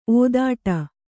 ♪ ōdāṭa